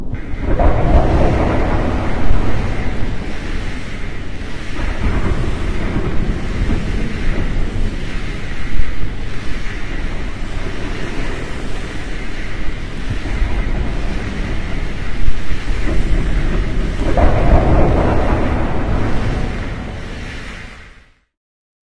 现场录音 " 天幕雨
描述：暴雨记录在塑料树冠下。
Tag: fieldrecording 暴雨 气象 性质 fieldrecording